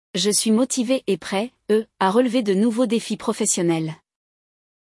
No episódio de hoje, você vai acompanhar uma jovem participando de uma entrevista de emprego em francês.